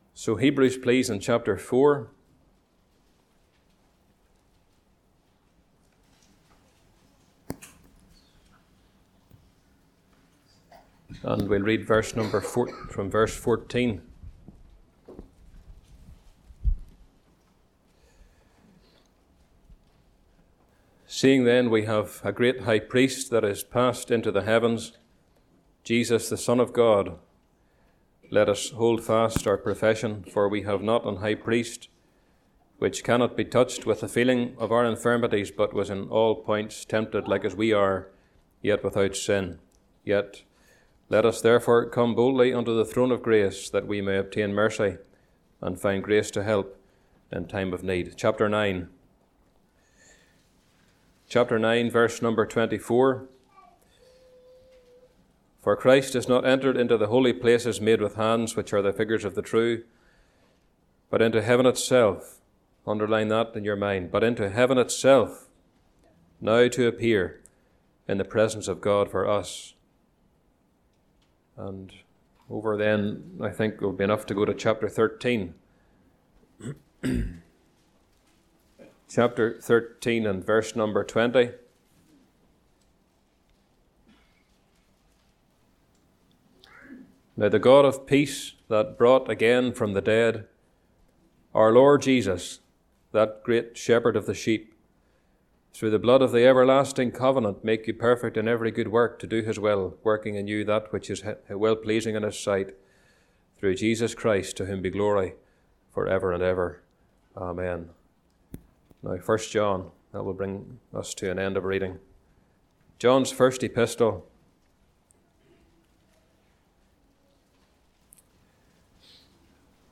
Location: Cooroy Gospel Hall (Cooroy, QLD, Australia)